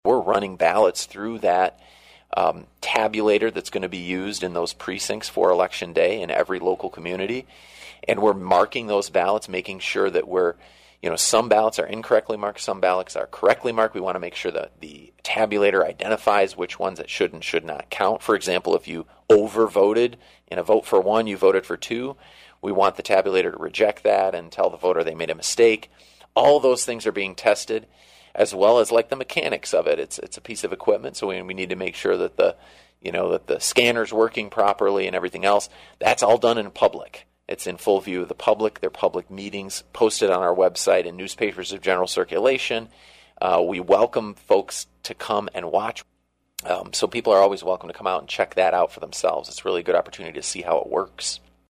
Meanwhile, all of the machinery to be used for those who do go to the polls in Ottawa County are getting the once-over in a series of logic and accuracy tests, according to Clerk and Register of Deeds Justin Roebuck, who explained during his monthly appearance on “WHTC Talk of the Town” on Wednesday.